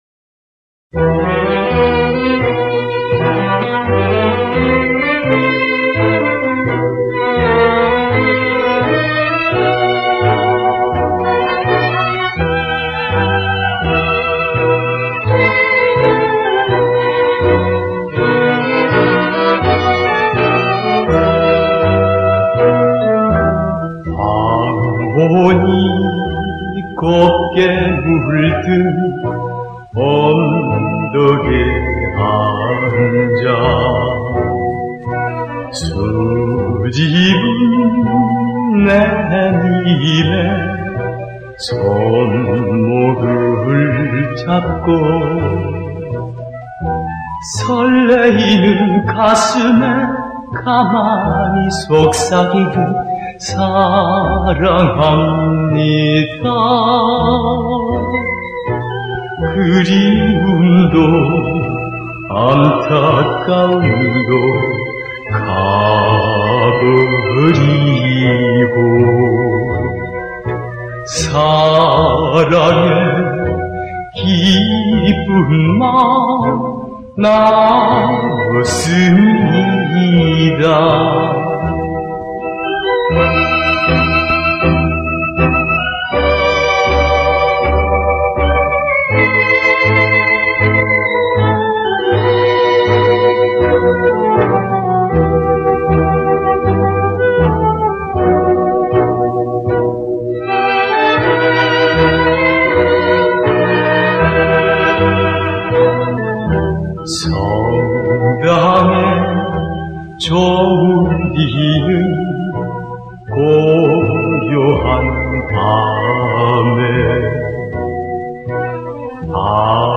※ 어느 지인이 음반 보내줘서 복각한 음원 임 음반은 돌려 주었네여